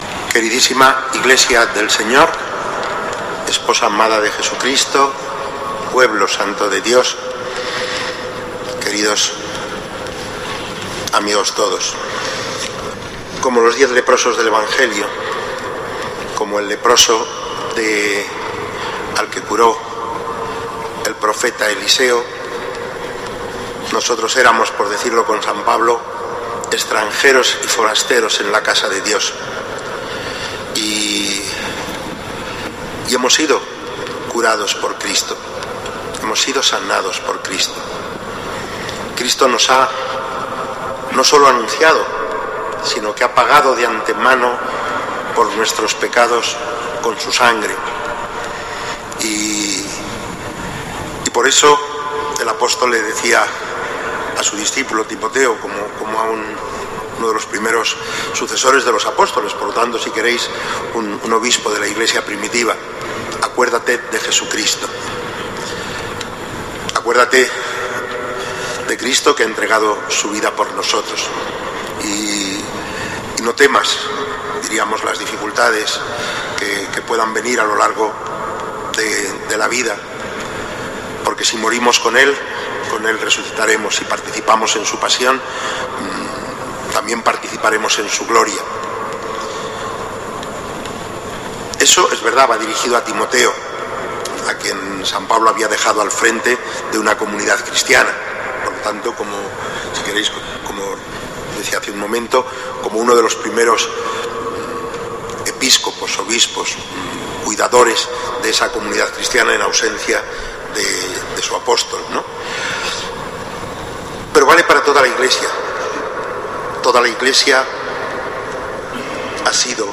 Homilía en la Eucaristía del XXVIII Domingo del Tiempo Ordinario en la Catedral, en la que habla del amor infinito de Dios por cada uno de nosotros, con nuestros límites y pecados, recordando la fidelidad de Dios como tuvo con Pedro a quien preguntó "Pedro, ¿me amas?".
Homilia_Arzobispo_9_octubre_Catedral.mp3